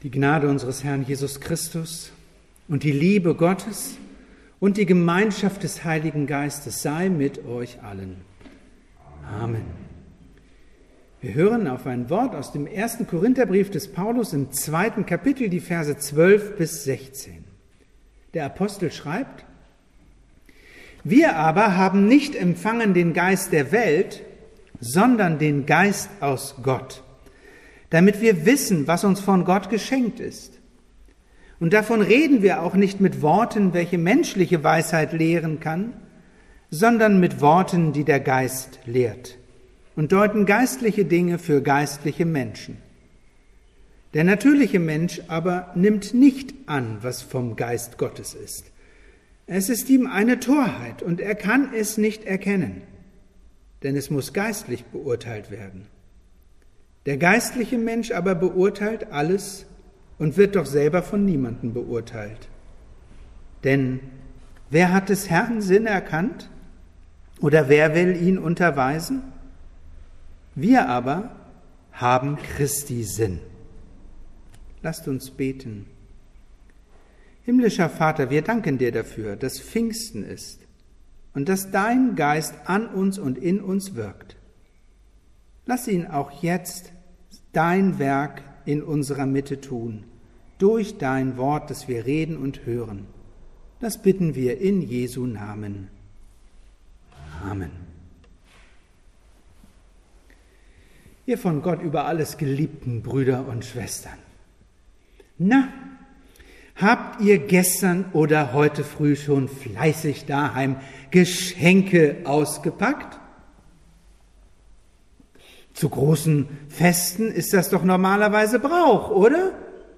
Predigtarchiv